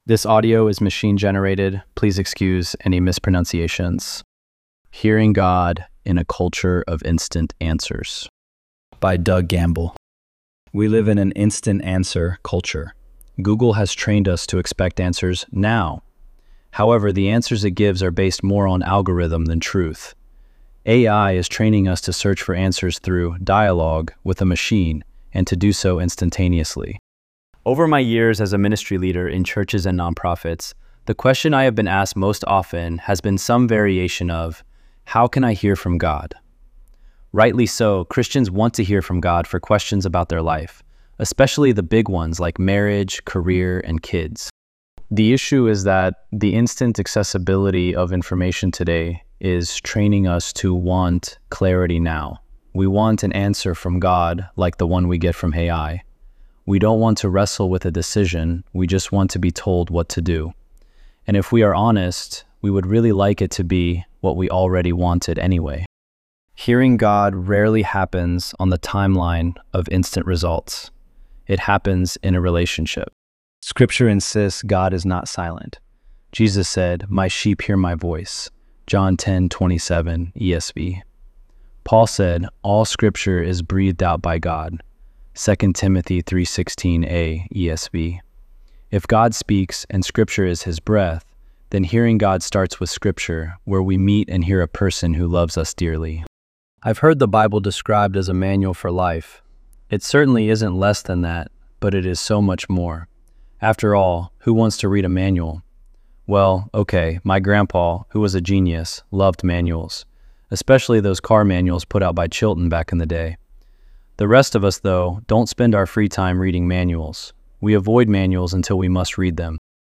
ElevenLabs_3_27.mp3